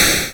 Added sounds for purple particle exploding and for getting points.
explode.wav